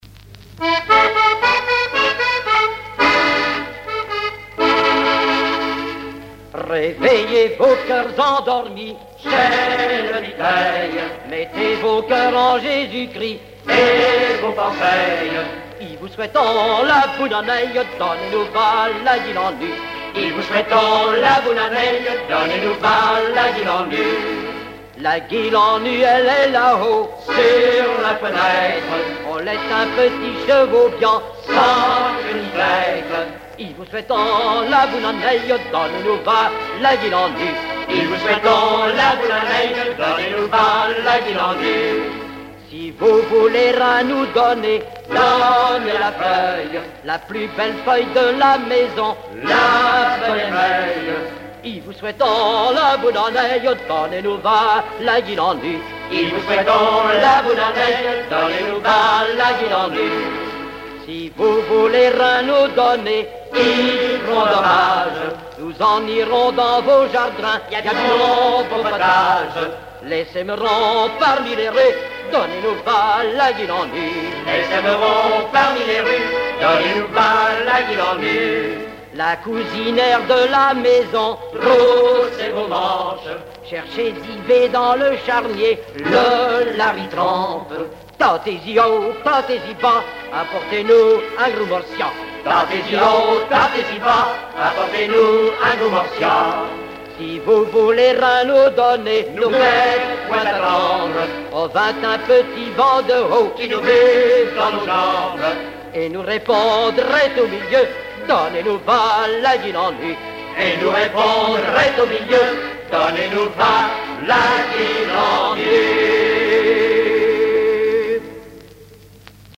circonstance : quête calendaire
Genre strophique
Pièce musicale inédite